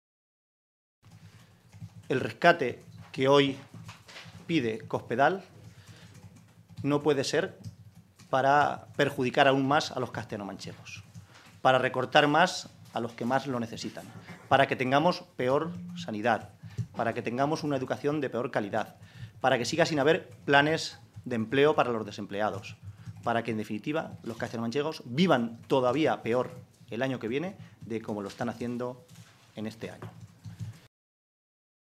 José Manuel Caballero, Secretario General del Grupo Parlamentario Socialista
Cortes de audio de la rueda de prensa